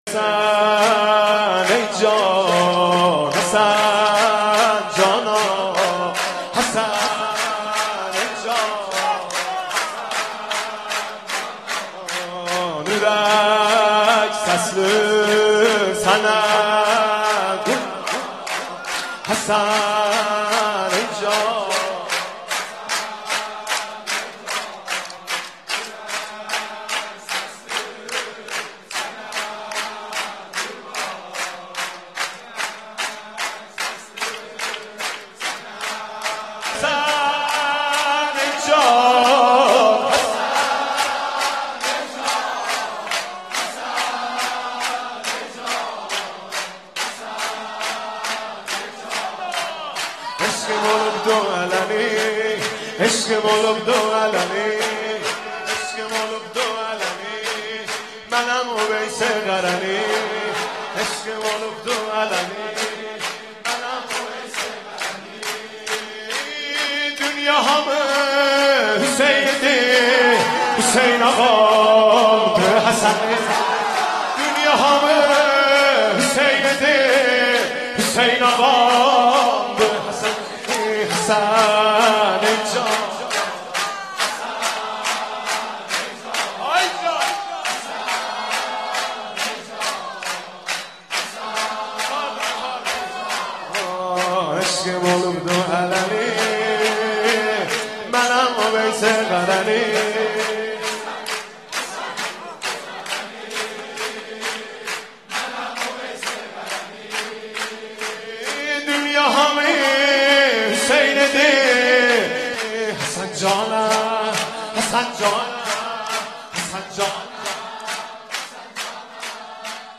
صوت/ مولودی به زبان آذری ویژه ولادت امام حسن(ع)
مولودی خوانی حاج مهدی رسولی به زبان آذری در جشن میلاد امام حسن علیه السلام را می شنوید.